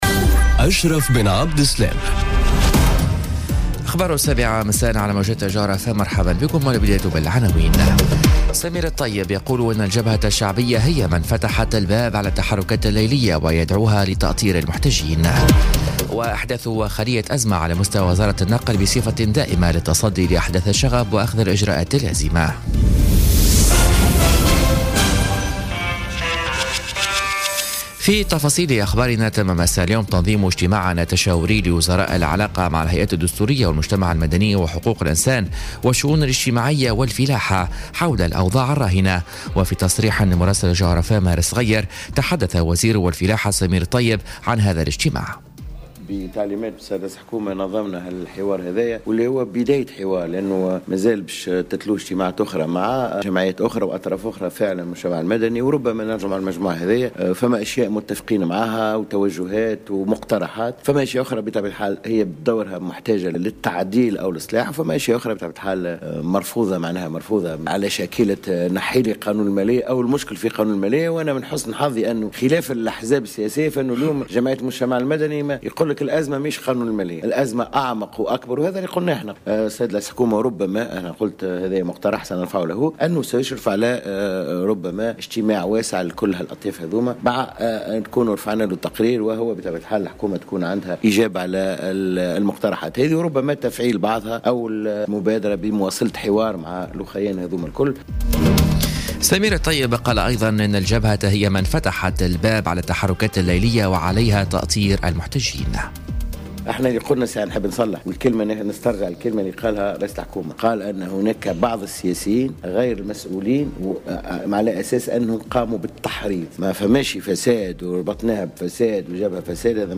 نشرة أخبار السابعة مساءً ليوم الخميس 11 جانفي 2017